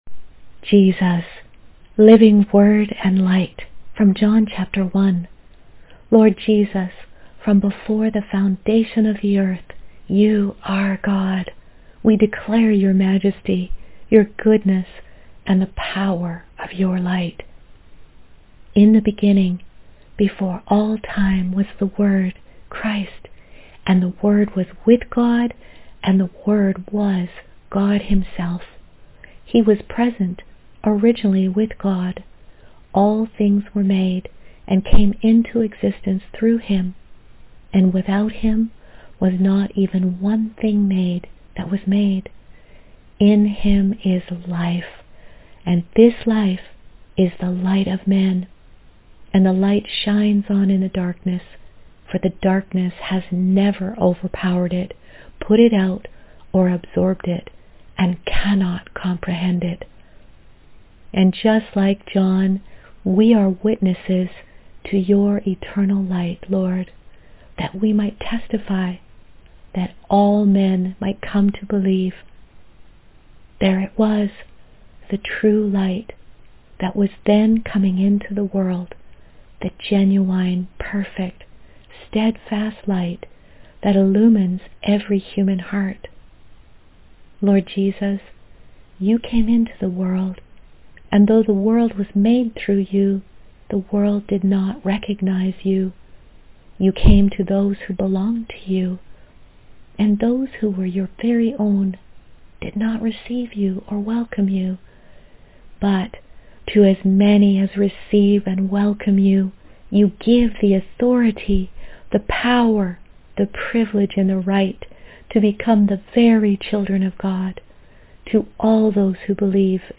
Living Word and Light — Prayer and Verse audio, from John 1 —